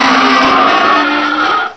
cry_not_scolipede.aif